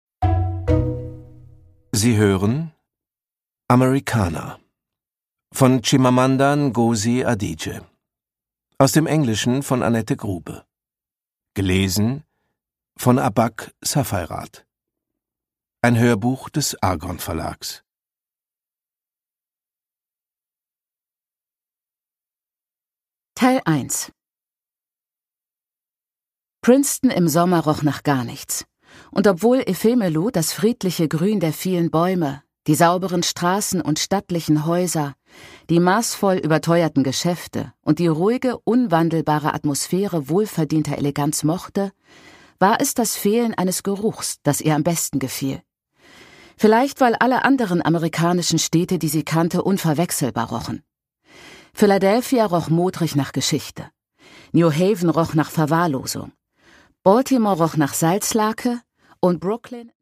Chimamanda Ngozi Adichie: Americanah (Ungekürzte Lesung)
Produkttyp: Hörbuch-Download